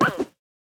Minecraft Version Minecraft Version 1.21.5 Latest Release | Latest Snapshot 1.21.5 / assets / minecraft / sounds / mob / armadillo / roll2.ogg Compare With Compare With Latest Release | Latest Snapshot